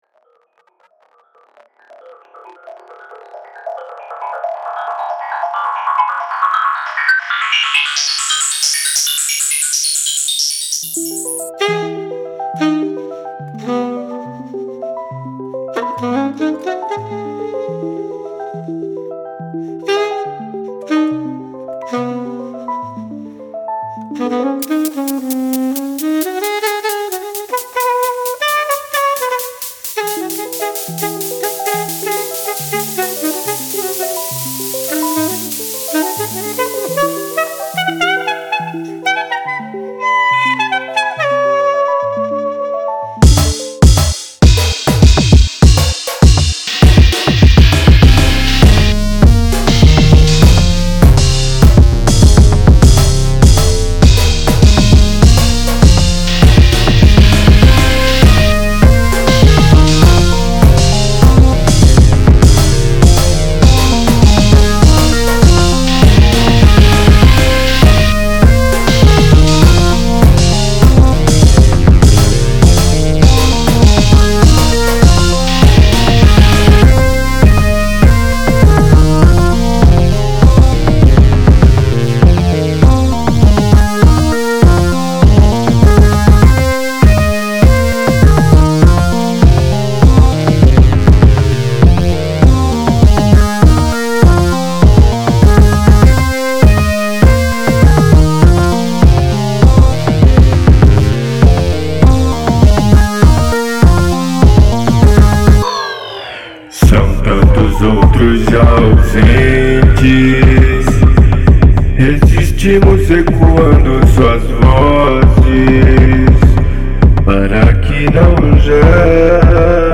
EstiloExperimental